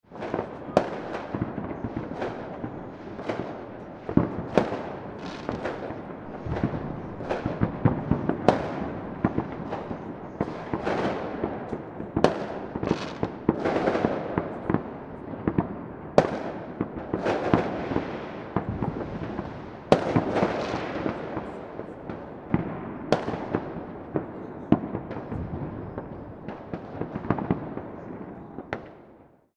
New Year Fireworks And Firecrackers Explosion Sound Effect
This high-quality explosion sound effect captures the energy and excitement of a lively holiday night, perfect for videos, party scenes, cinematic projects, and festive content.
New-year-fireworks-and-firecrackers-explosion-sound-effect.mp3